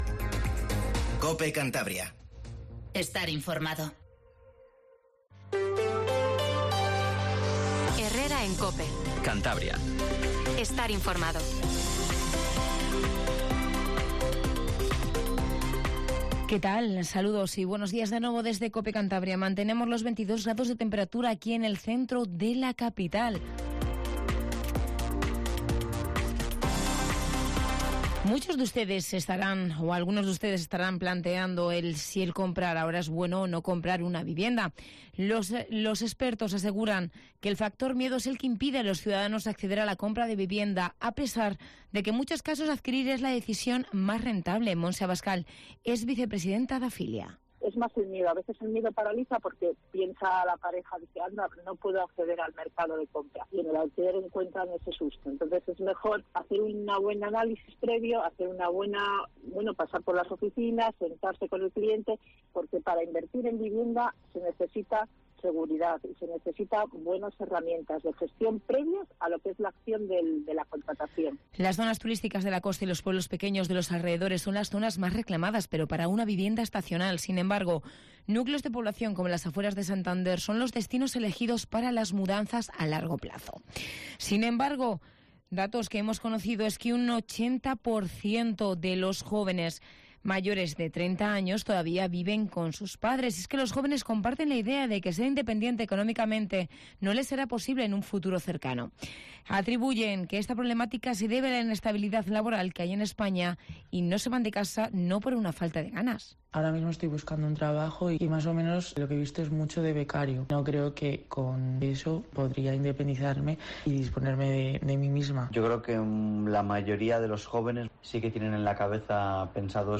Informativo Matinal Cope 08:20